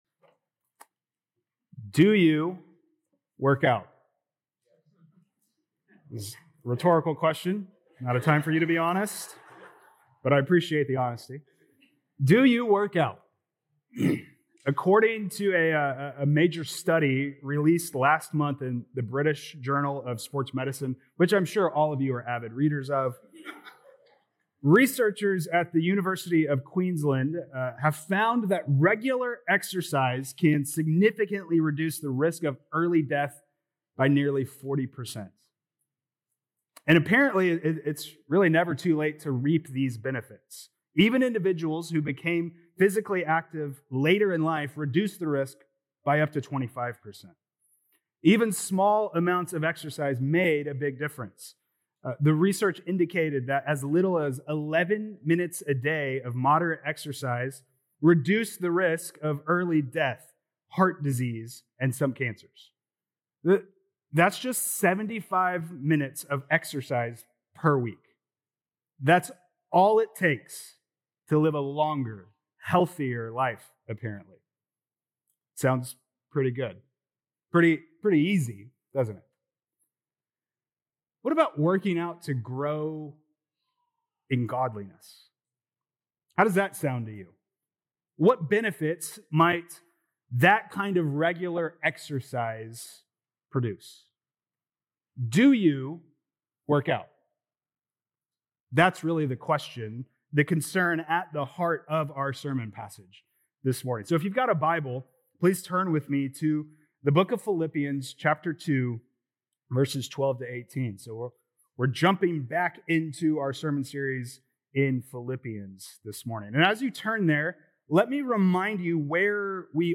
August 17th Sermon | Philippians 2:12-18